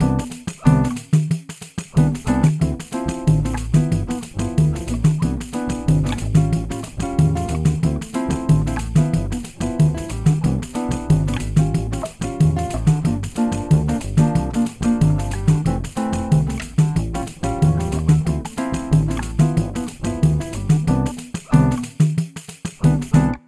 Ensimmäinen osa sisältää perustietoa pasuunan toiminnasta ja sen erityispiirteistä, toisessa osassa harjoitukset ovat brasilialaista samba-musiikkityyliä.
Apumateriaaliksi nauhoitin kymmenen toistoa siten, että muusikkoa säestävät harmoniset ja rytmiset instrumentit. Kaikki toistot äänitettiin kotistudiossani.